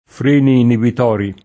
inibitore [ inibit 1 re ] s. m.; f. -trice — es.: freni inibitori [ fr % ni inibit 1 ri ] (psicol.), se inteso come pl. di inibitore ; e freni inibitori [ fr % ni inibit 0 ri ], se inteso come pl. di inibitorio